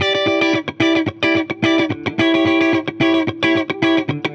Index of /musicradar/dusty-funk-samples/Guitar/110bpm
DF_70sStrat_110-E.wav